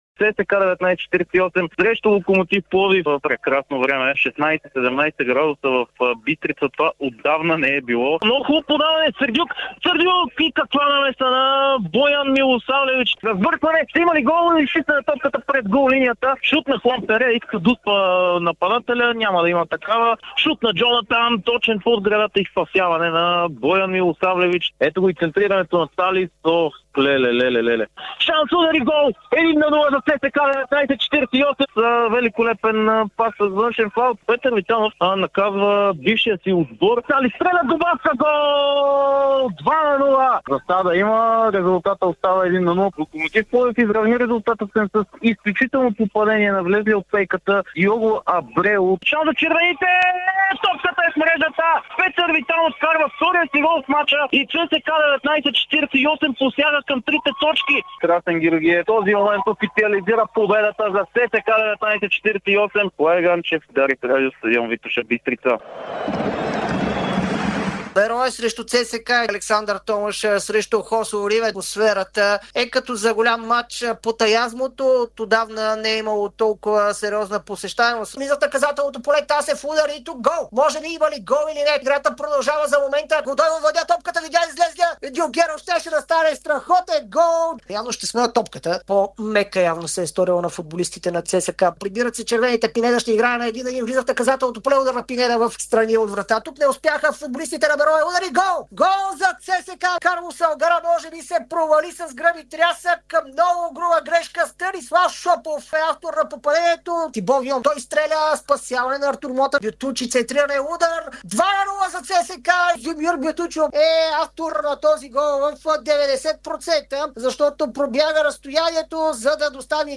След края на 24-ия кръг на efbet Лига продължаваме традицията с най-емоционалните моменти от това, което чухте по Дарик радио – за най-интересните голове, участието на докторите, картоните и честитяването на новата 2026 г. още през месец март на 2025 г.